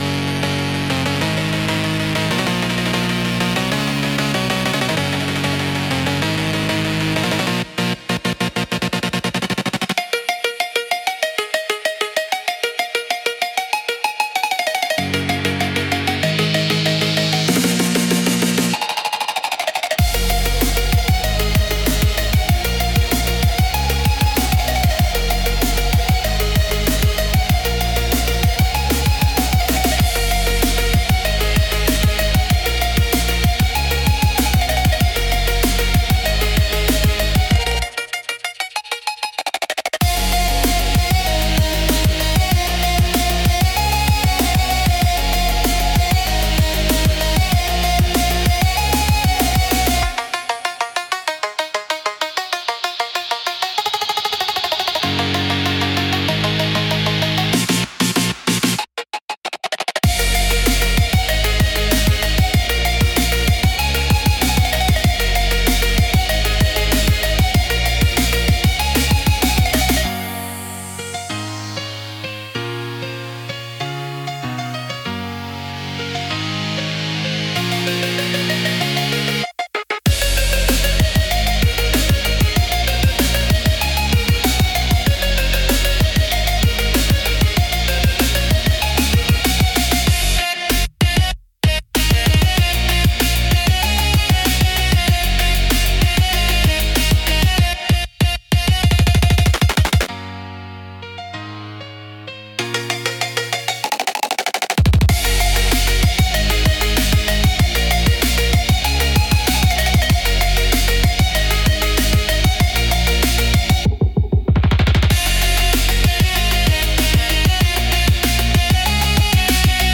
Genre: Phonk Mood: Energetic Editor's Choice